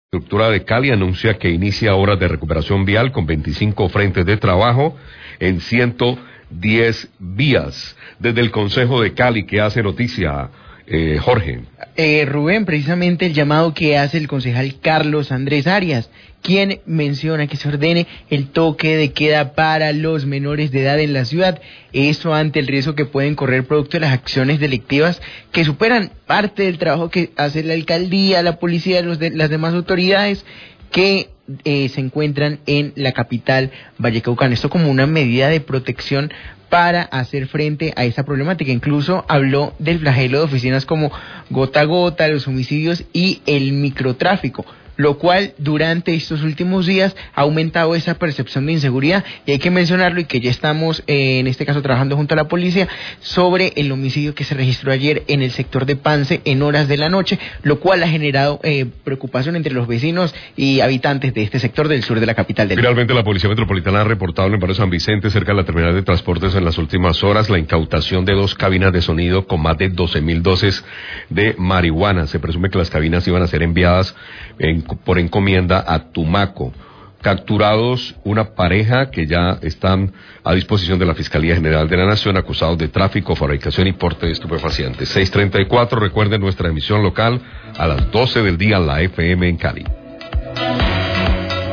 Resumen de la principales noticias de Cali, La FM 634am
Radio